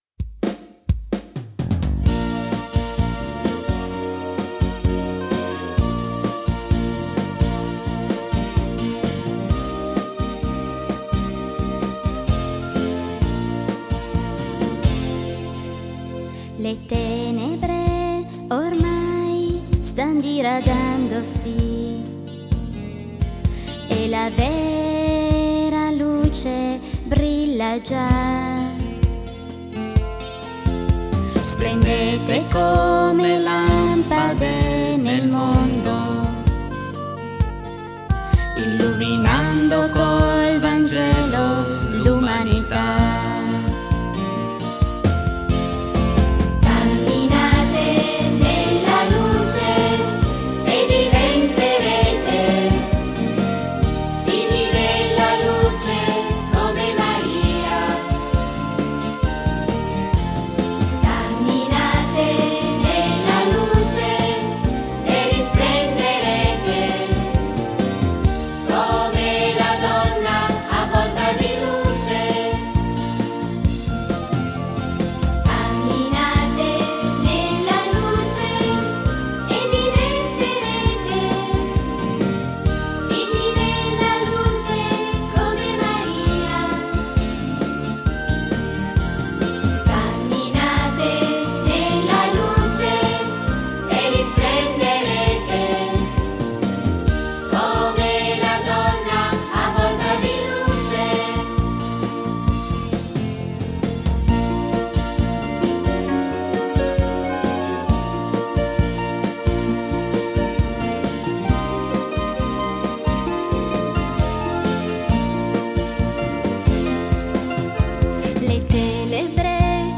Canto per la Decina di Rosario e Parola di Dio: Camminate nella luce